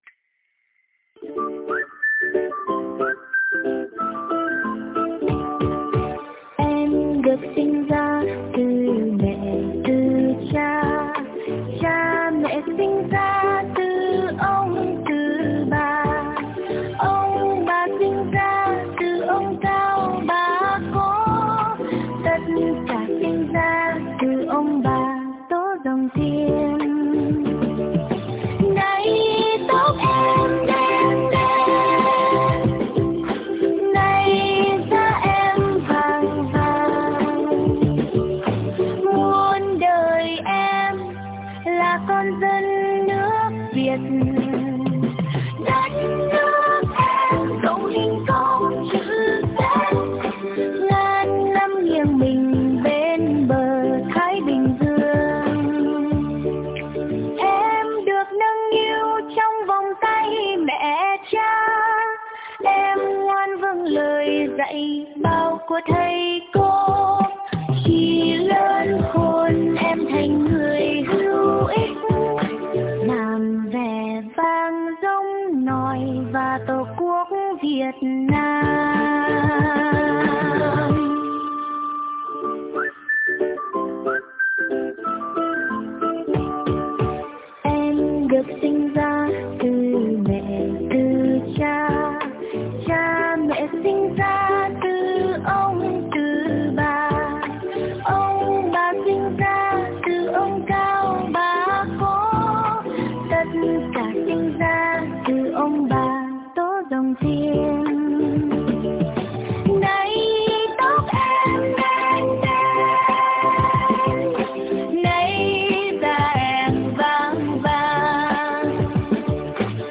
Nhạc thiếu nhi